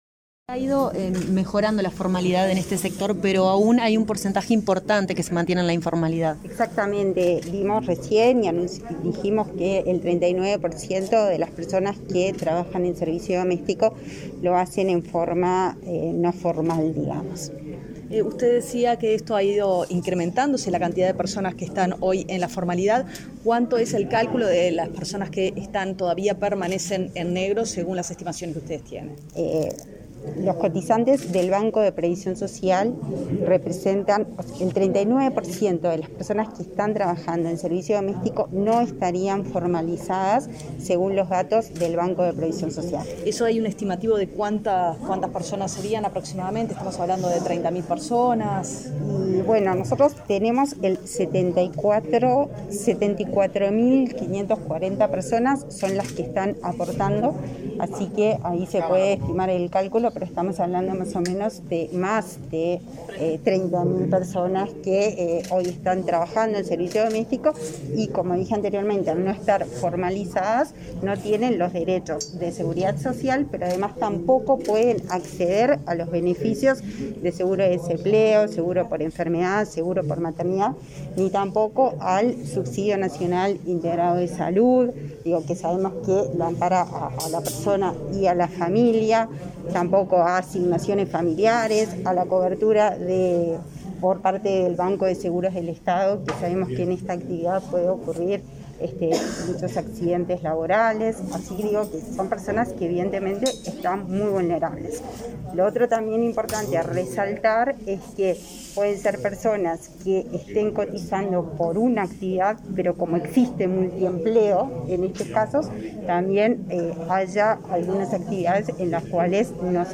Declaraciones a la prensa de la directora del Instituto Nacional de las Mujeres, Mónica Bottero
Declaraciones a la prensa de la directora del Instituto Nacional de las Mujeres, Mónica Bottero 19/08/2021 Compartir Facebook X Copiar enlace WhatsApp LinkedIn Tras la conferencia de prensa por el Día de las Trabajadoras Domésticas, realizada este 19 de agosto en la sede del Ministerio de Trabajo, la titular de Inmujeres brindó declaraciones a la prensa.